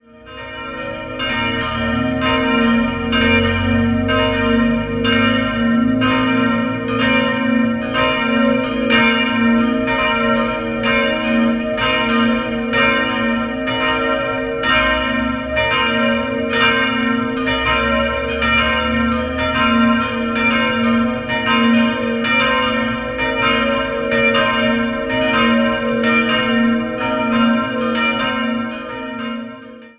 Sie wurde 1907 errichtet und orientiert sich am neuromanischen Baustil. 3-stimmiges Gloria-Geläut: b'-c''-es'' Die kleine Glocke stammt von Hamm aus dem Jahr 1907, die mittlere von Petit&Edelbrock aus dem Jahr 1950 und die große ergänzte 1982 Rudolf Perner aus Passau.